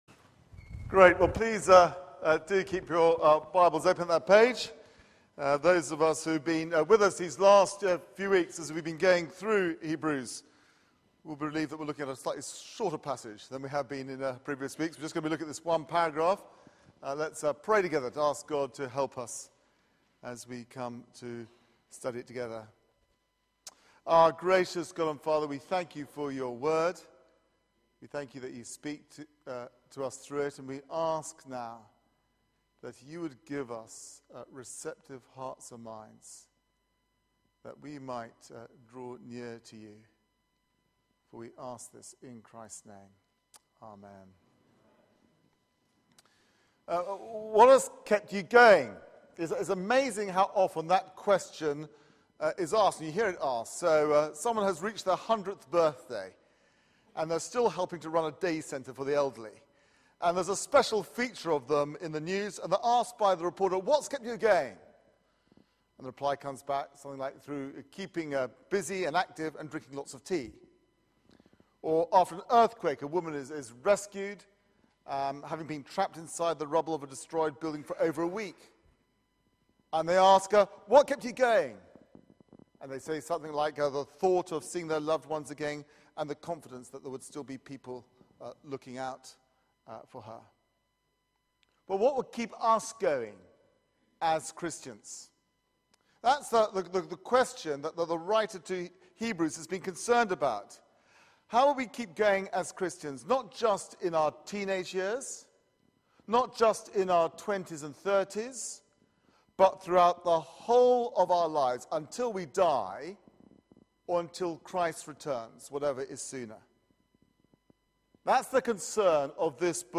Media for 6:30pm Service on Sun 01st Dec 2013 18:30 Speaker
Series: Jesus is better Theme: How to keep going Sermon